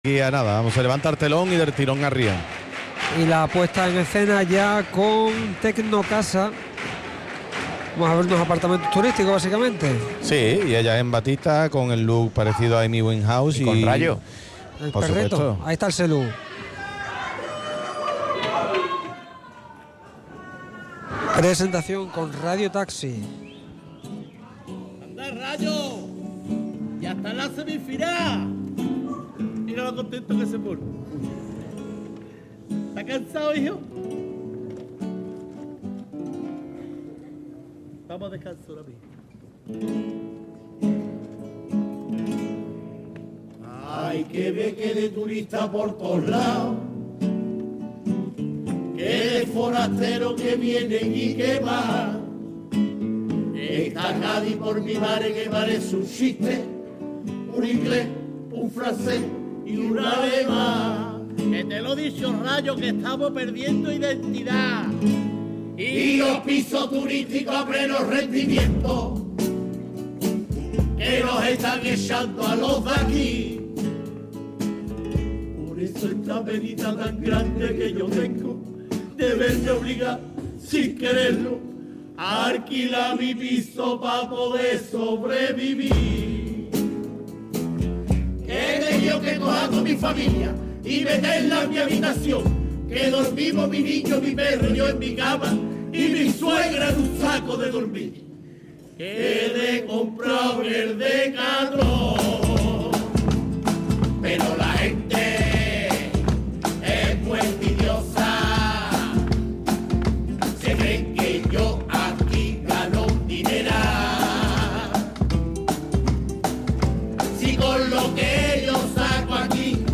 Chirigota : Apartamentos turísticos Juani Wainjaus Semifinal